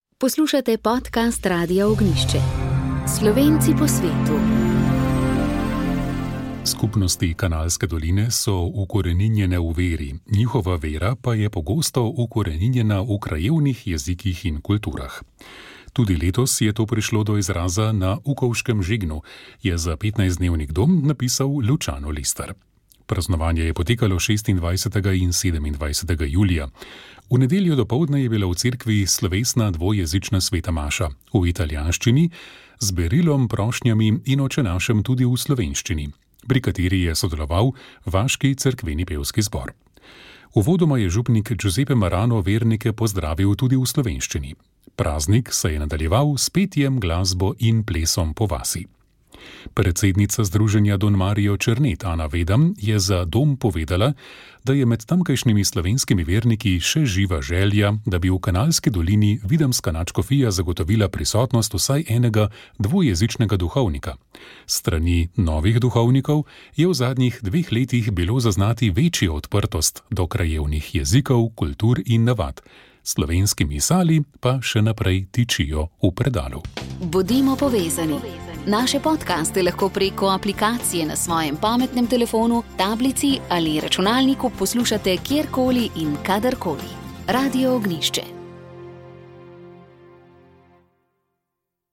V »Pogovoru o« smo se tokrat spraševali »Kje je naša elita?« V razpravi o elitah v slovenski družbi, ki smo jo pripravili skupaj s civilno iniciativo Prebudimo Slovenijo, so svoje poglede predstavili trije ugledni gostje.